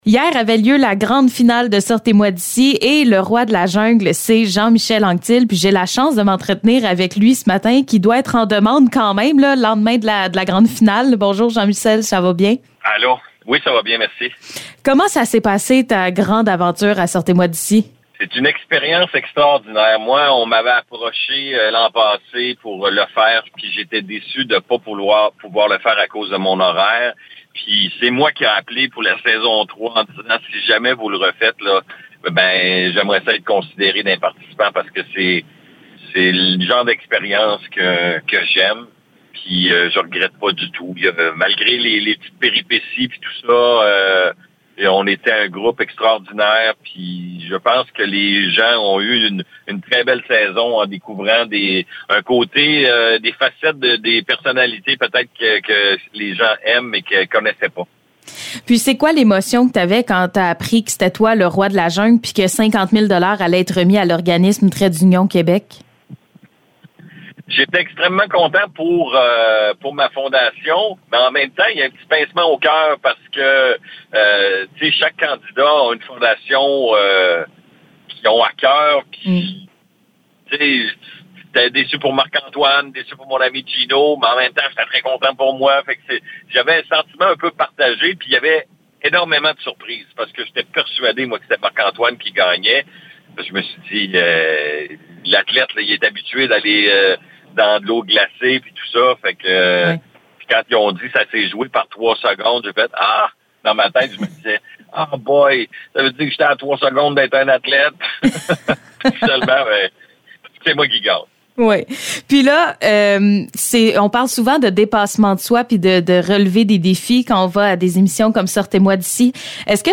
Entrevue Jean-Michel Anctil gagnant de Sortez-moi d’ici